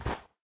snow2.ogg